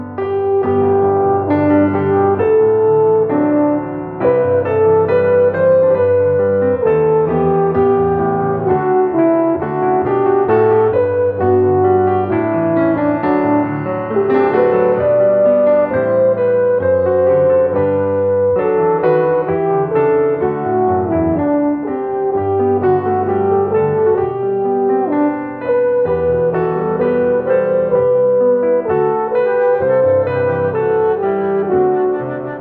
arranged for piano and light instrumentals